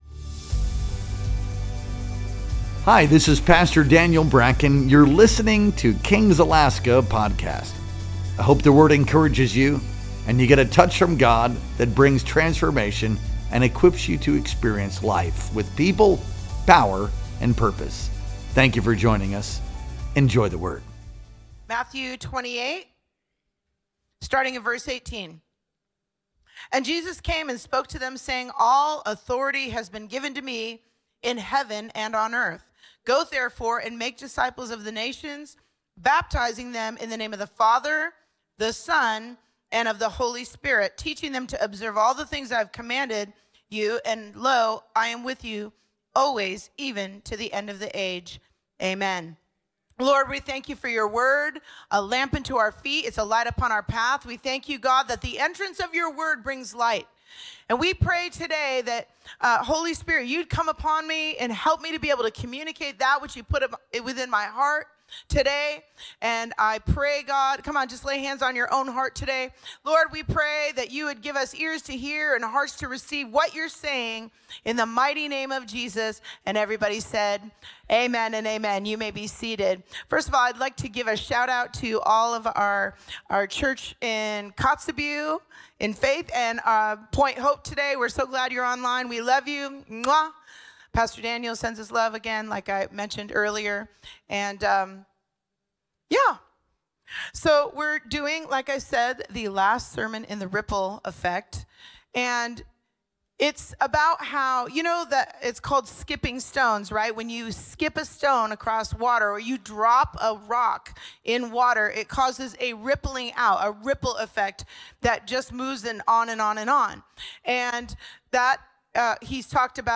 Our Sunday Worship Experience streamed live on February 2nd, 2025.